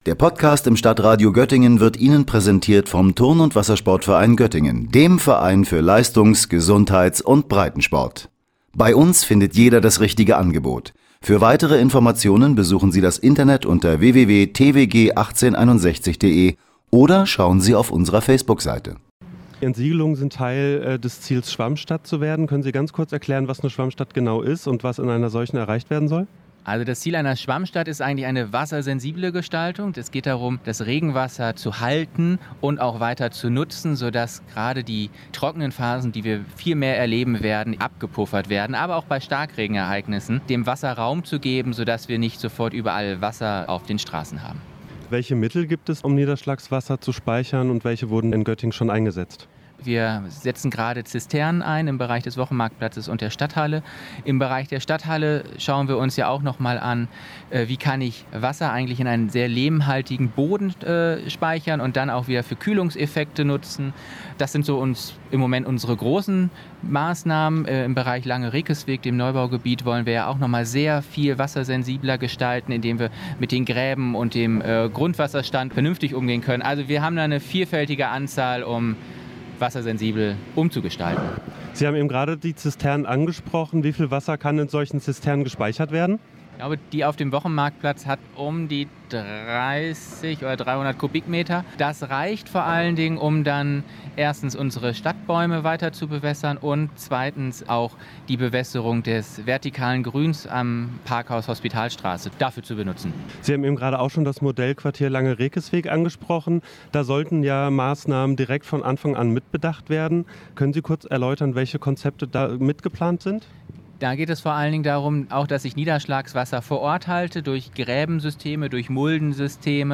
Beiträge > Versuch einer Oase in der Betonwüste: Stadtbaurat Frithjof Look im Gespräch über die Stadtbegrünung in Göttingen - StadtRadio Göttingen